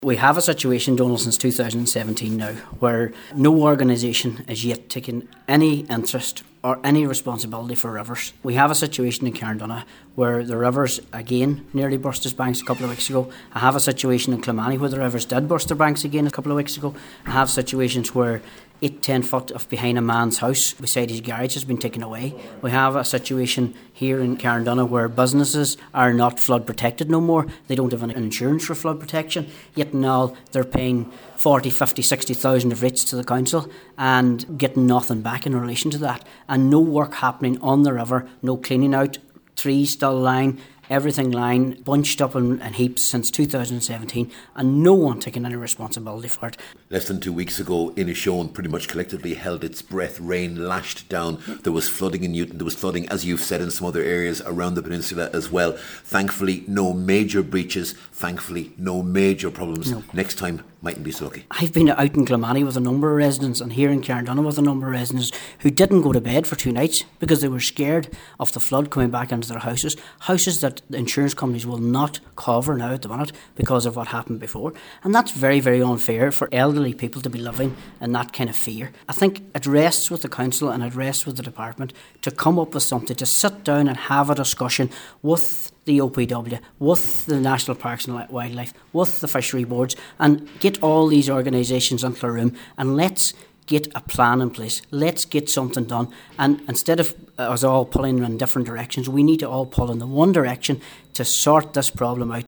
Cllr Martin Mc Dermott told the meeting that just last week, people spent two days in fear of a repeat of the devastation of 2017, some of whom can no longer get insurance.